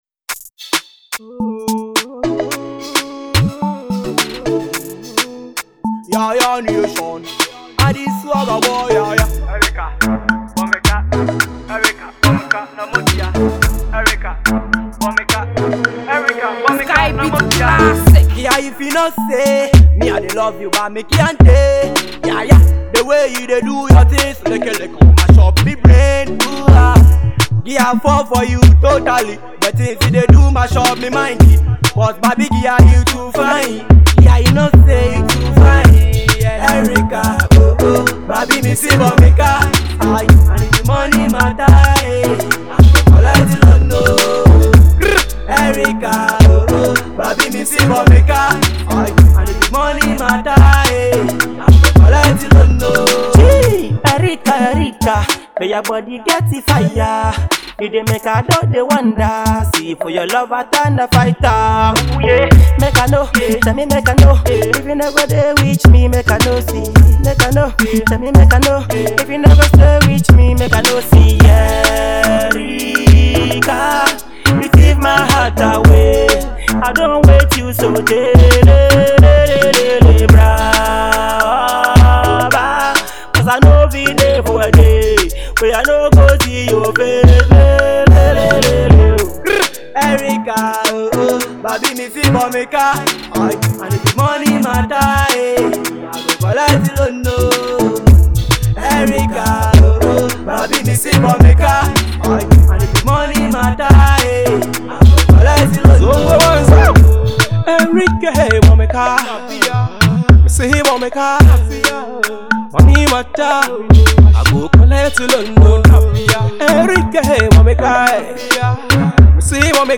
Afrobeat
love song